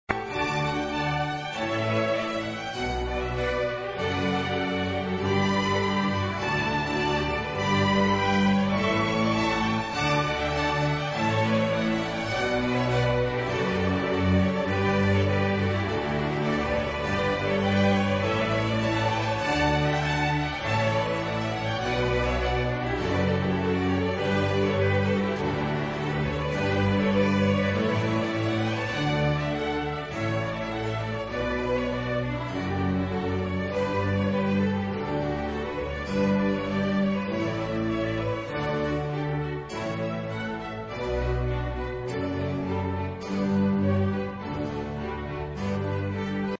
Kategori Klassisk